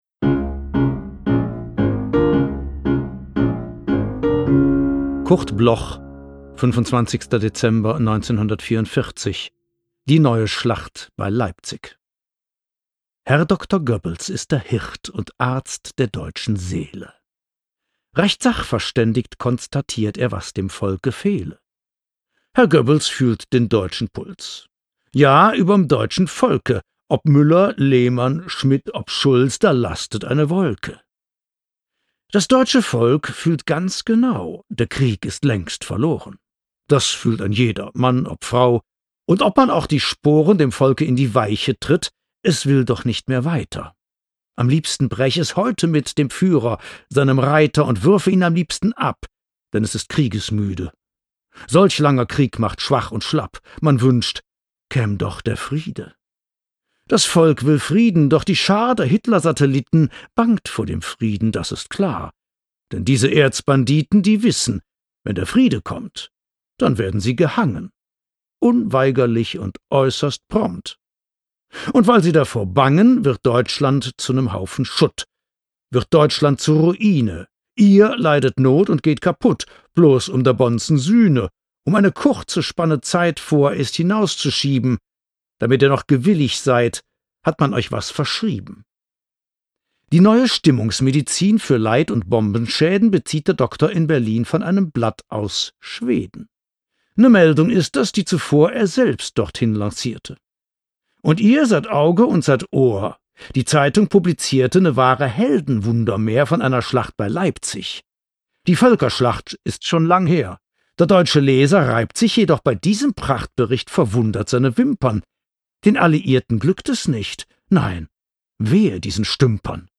Frank-Schaetzing-Die-neue-Schlacht-bei-Leipzig_mit-Musik_raw.m4a